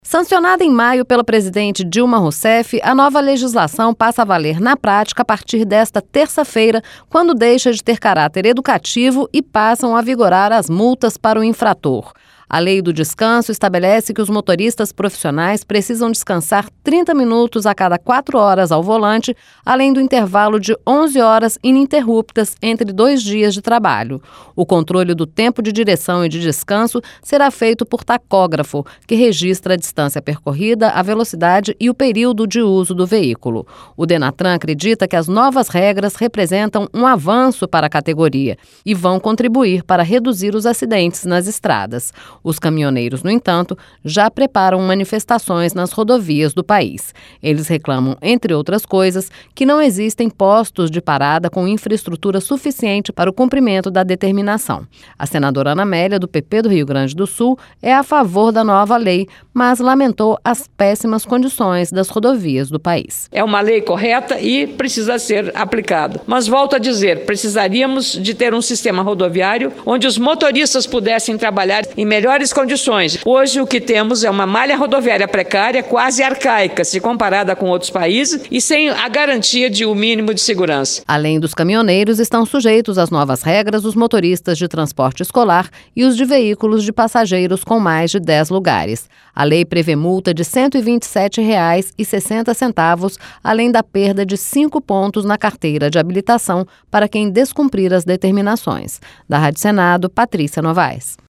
A senadora Ana Amélia, do PP do Rio Grande do Sul, é a favor da nova lei, mas lamentou as péssimas condições das Rodovias do País.